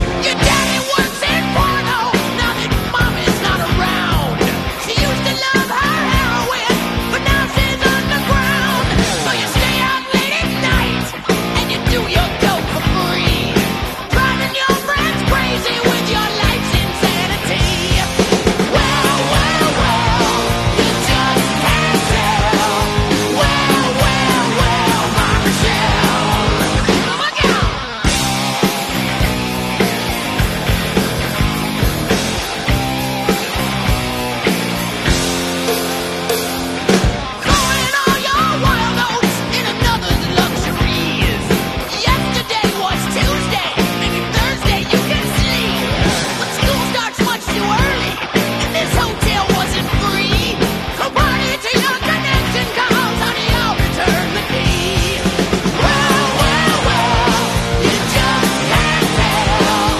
American hard rock band
lead guitar
bass guitar